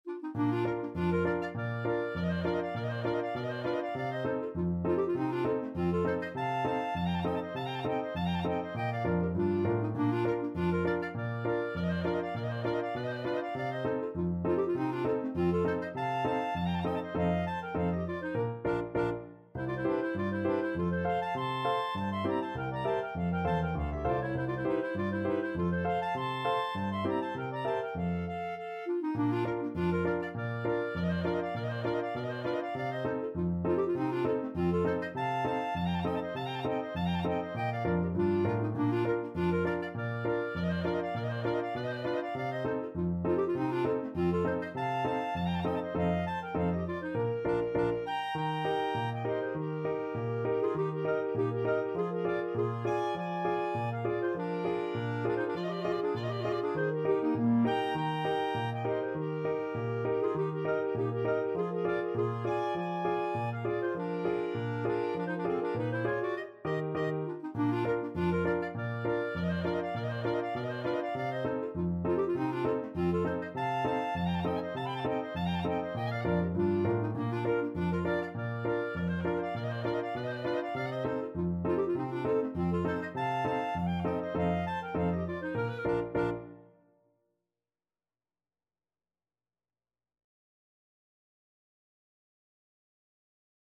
Clarinet Duet version
2/4 (View more 2/4 Music)
Traditional (View more Traditional Clarinet Duet Music)